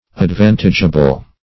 advantageable - definition of advantageable - synonyms, pronunciation, spelling from Free Dictionary